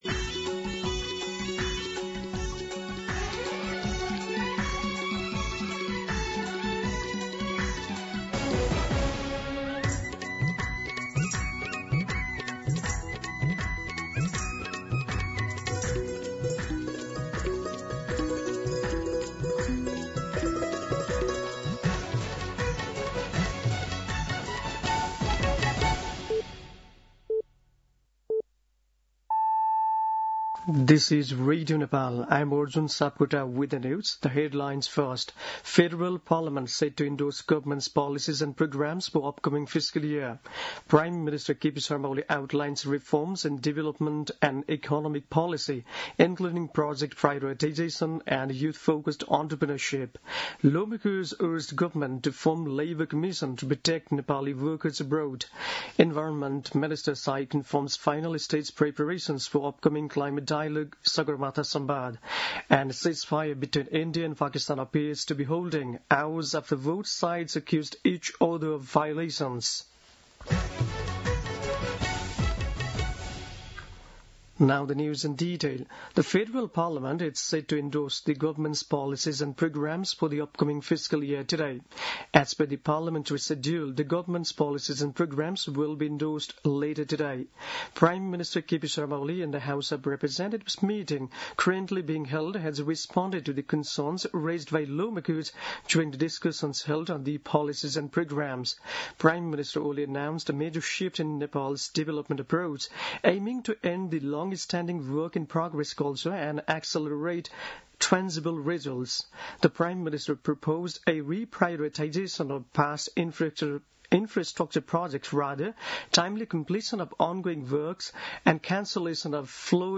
दिउँसो २ बजेको अङ्ग्रेजी समाचार : २८ वैशाख , २०८२
2-pm-English-News-2.mp3